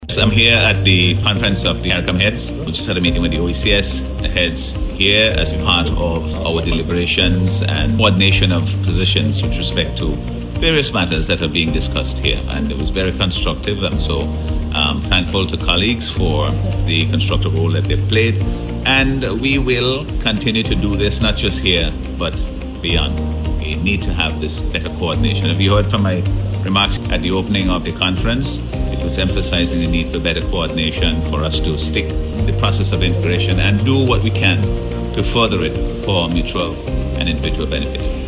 He made the statement as he delivered remarks on the sidelines of the OECS Heads of Government meeting, held alongside the CARICOM summit on February 25th.